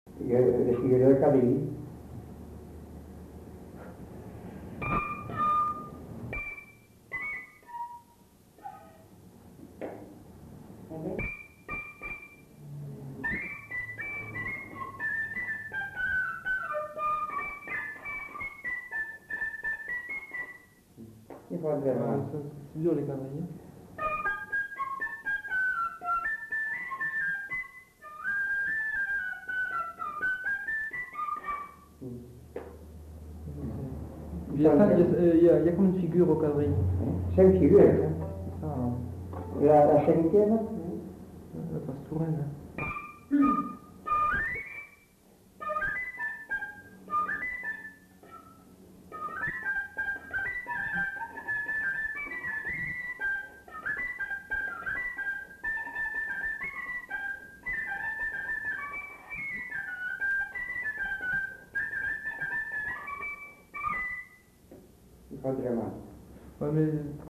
Aire culturelle : Bazadais
Lieu : Bazas
Genre : morceau instrumental
Instrument de musique : fifre
Danse : quadrille (5e f.)